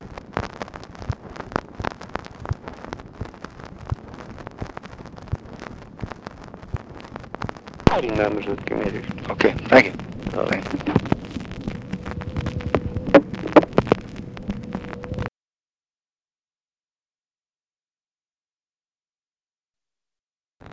Conversation with RICHARD RUSSELL, July 26, 1965
Secret White House Tapes